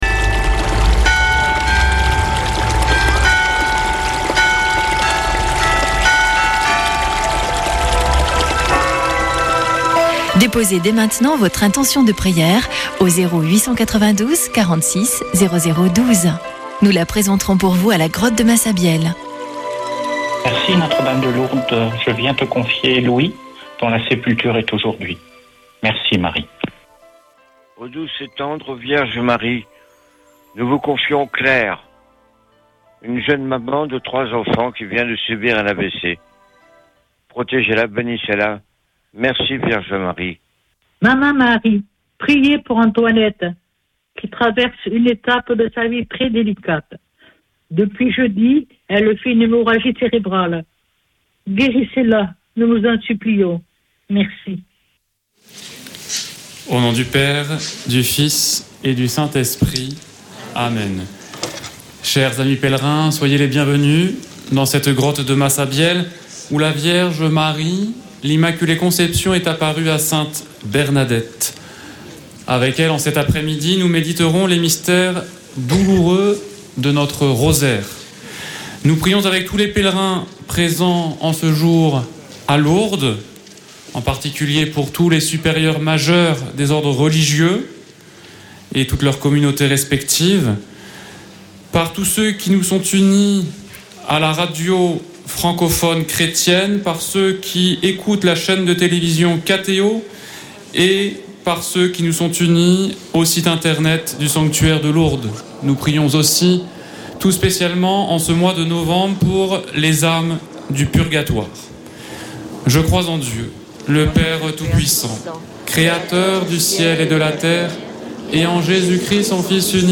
Chapelet de Lourdes du 18 nov.
Une émission présentée par Chapelains de Lourdes